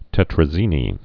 (tĕtrə-zēnē)